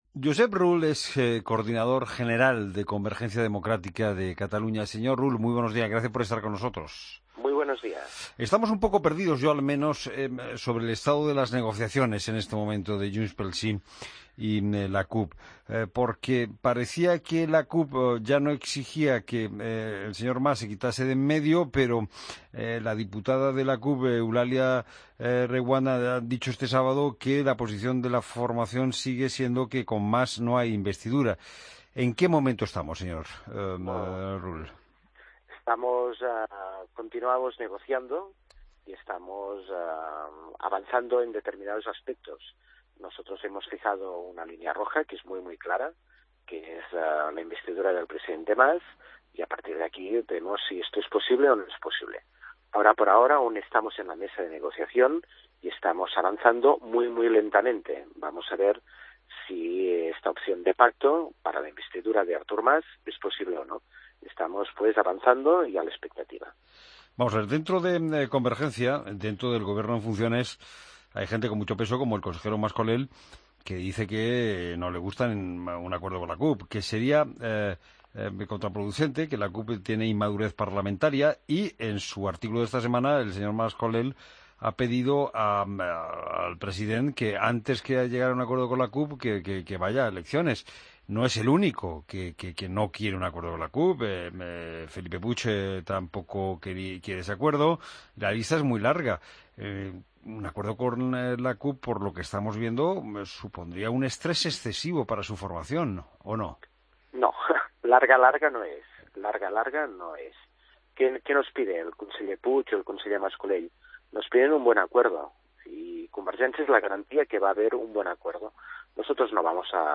Escucha la entrevista a Josep Rull en La Mañana del Fin de Semana